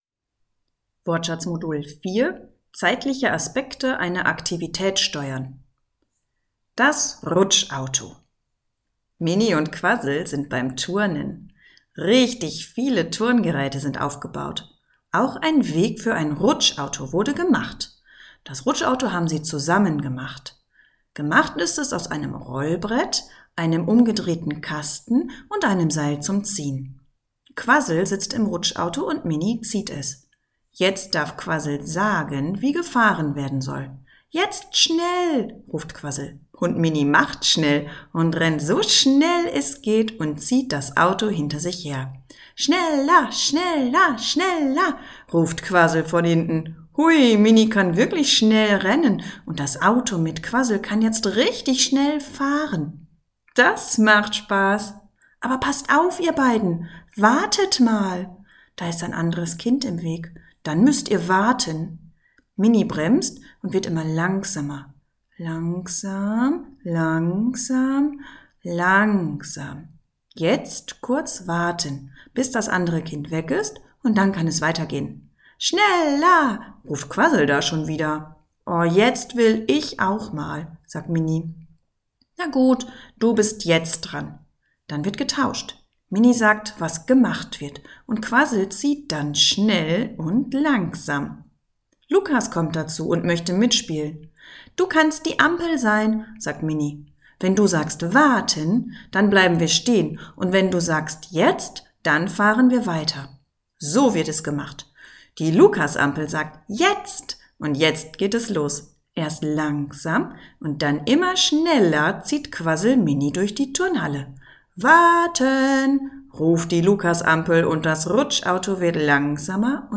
Die Aufnahmen sind mit viel Intonation und wörtlicher Rede eingesprochen, damit die Kinder ein lebhaftes Hörerlebnis haben.